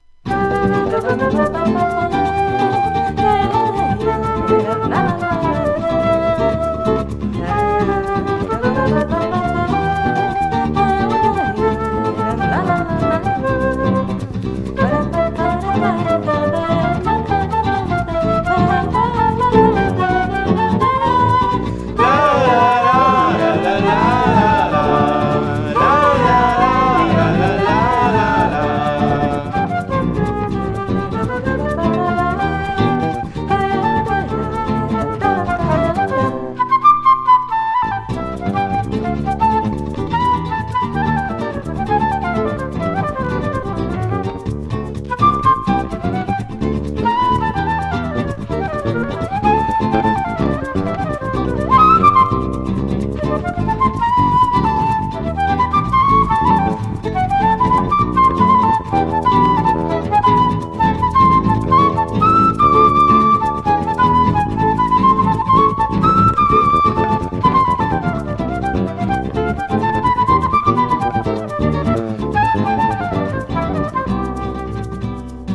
大推薦レア・ドイツ産ブラジリアン・アルバム！！！